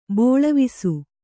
♪ bōḷavisu